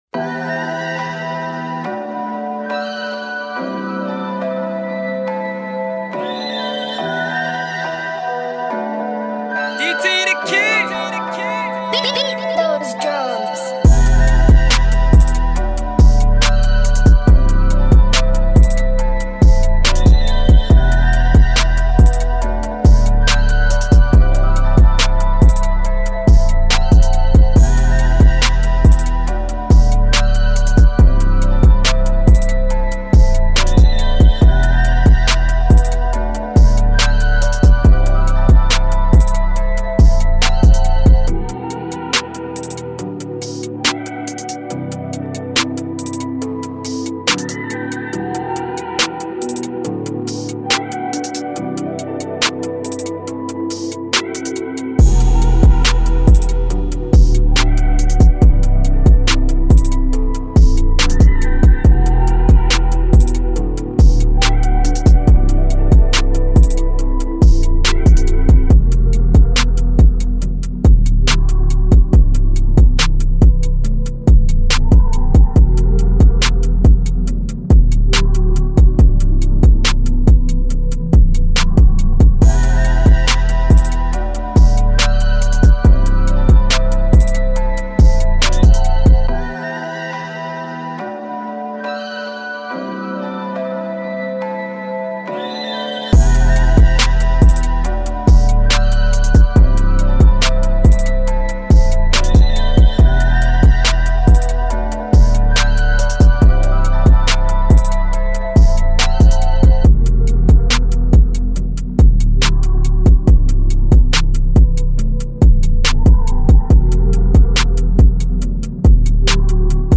Rap Instrumental